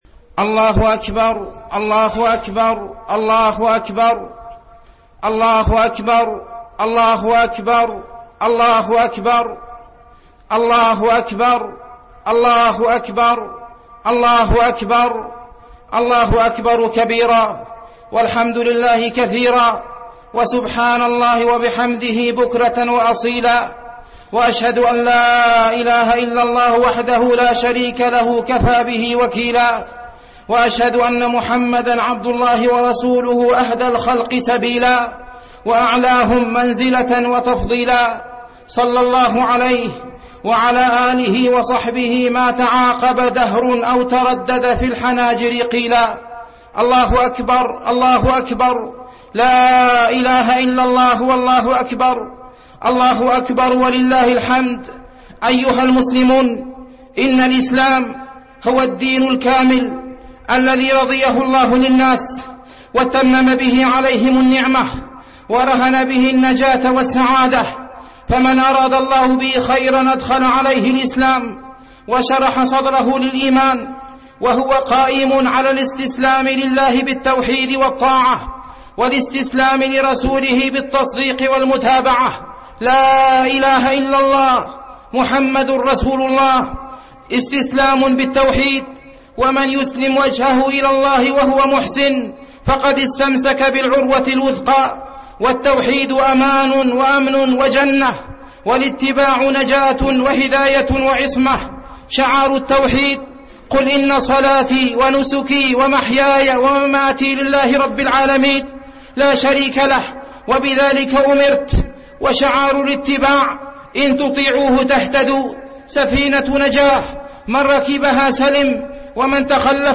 خطبة عيد الفطر 1436هـ